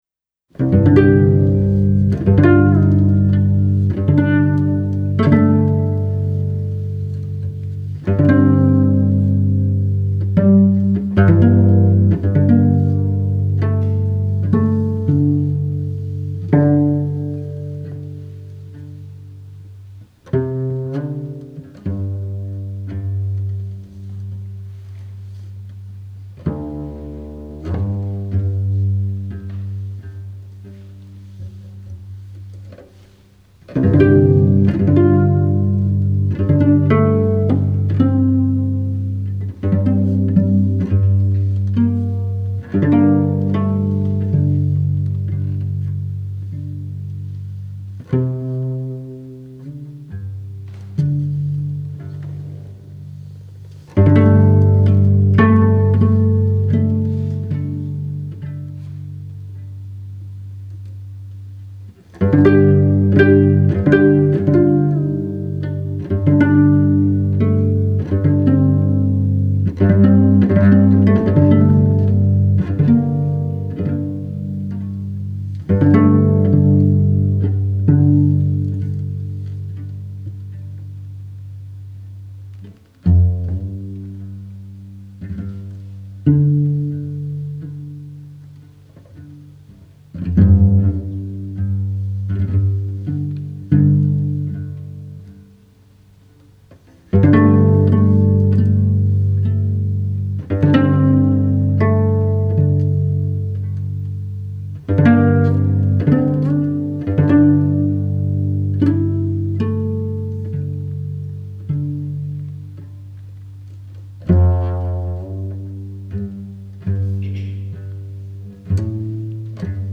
commissioned music by Seattle cellist